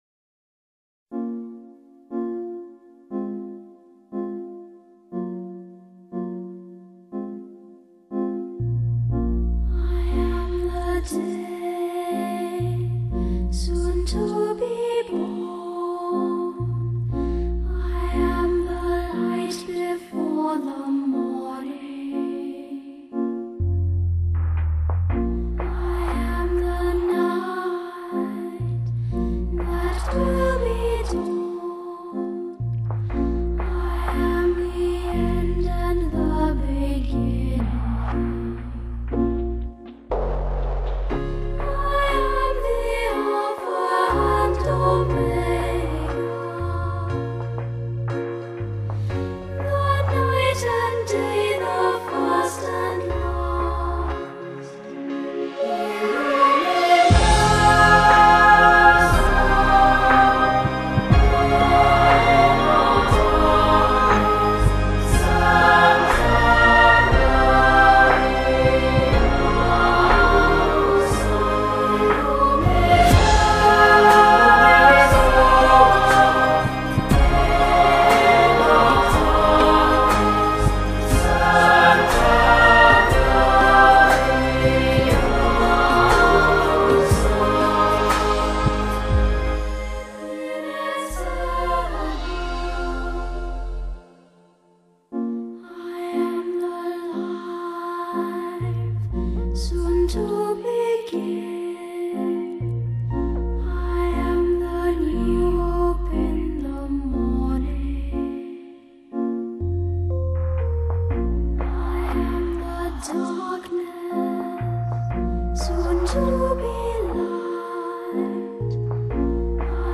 类型:古典人声